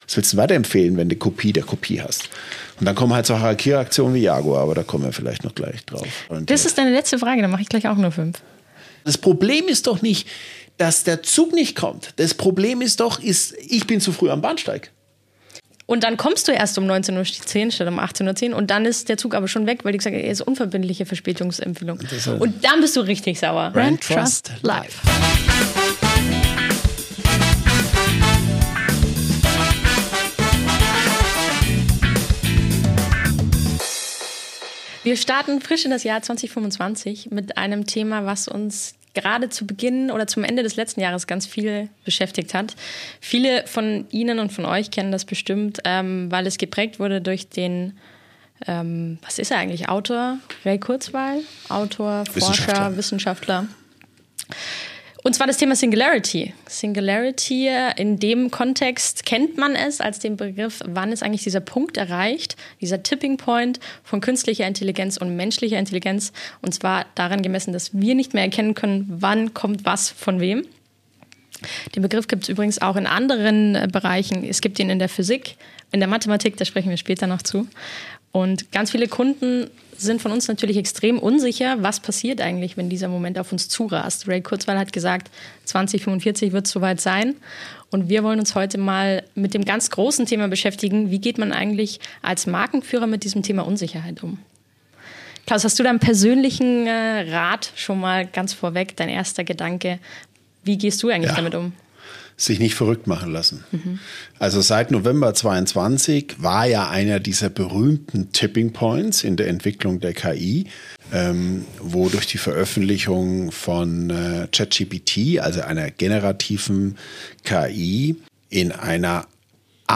Ein spannendes Gespräch, das Markenverantwortliche inspirieren wird, sich den Herausforderungen der heutigen Zeit zu stellen.